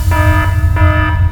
air_shock_alarm.wav